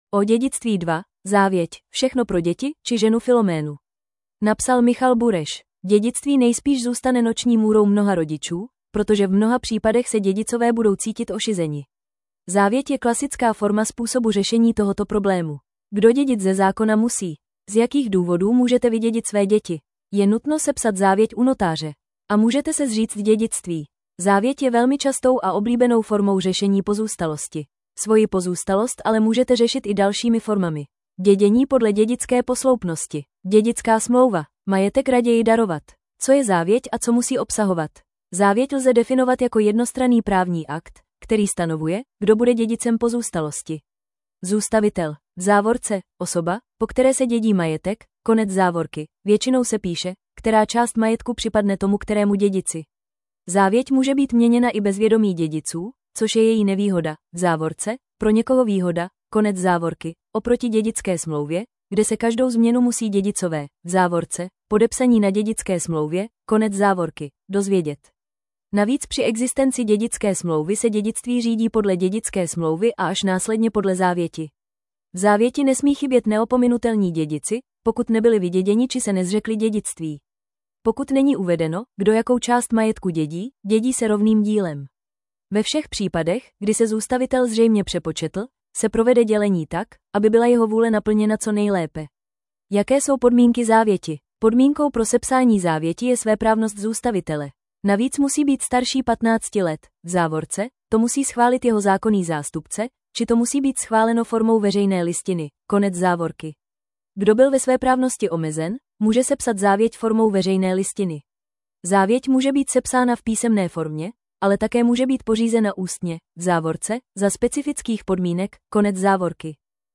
Tento článek pro vás načetl robotický hlas.